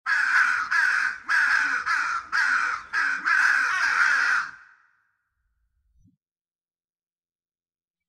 Crow Caw Halloween Sound Button - Free Download & Play